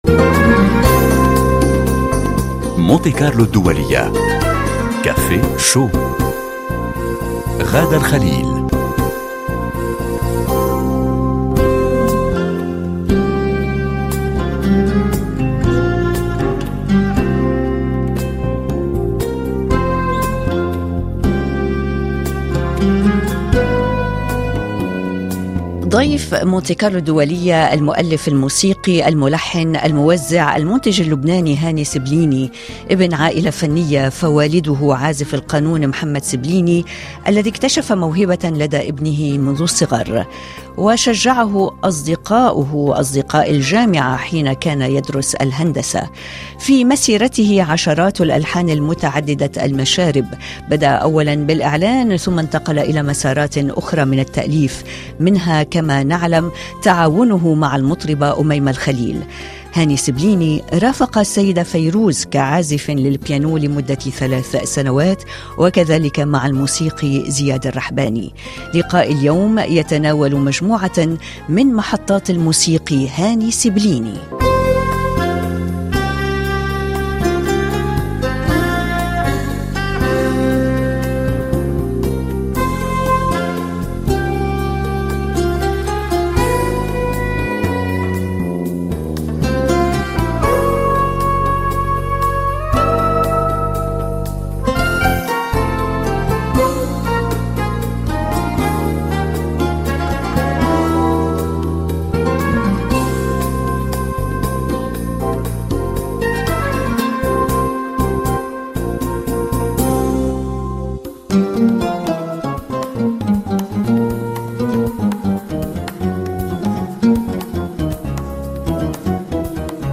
مجلة صباحية يومية يلتقي فيها فريق كافيه شو مع المستمعين للتفاعل من خلال لقاءات وفقرات ومواضيع يومية من مجالات مختلفة : ثقافة، فنون، صحة، مجتمع، بالاضافة إلى الشأن الشبابي عبر مختلف بلدان العالم العربي.